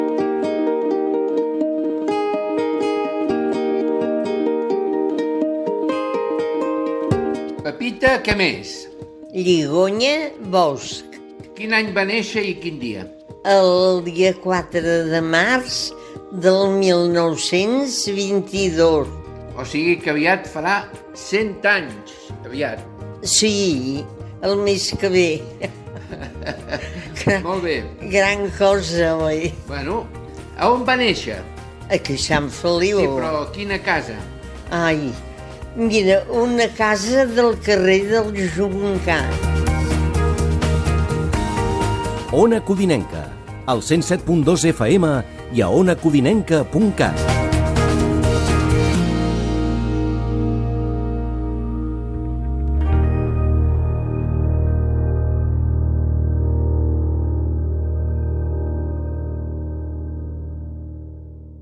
indicatiu de l'emissora
Banda FM